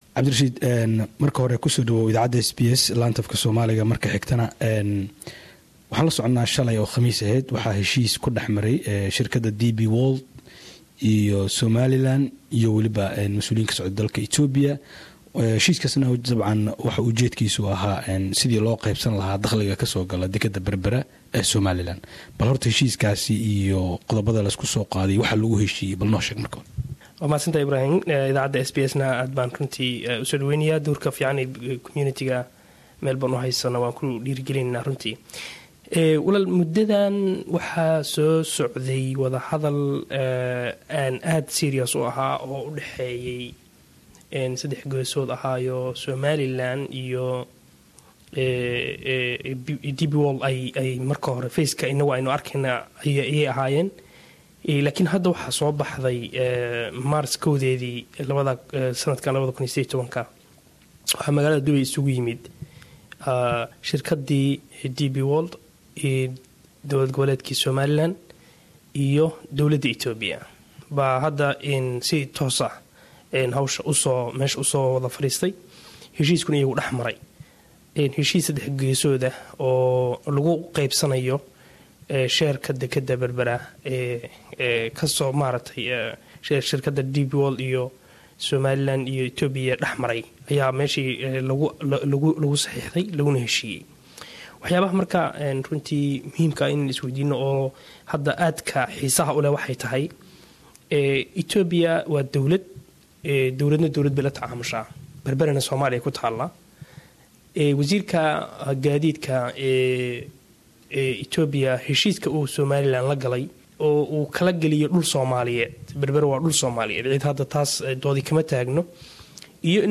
Wareysi gaar ah Dp World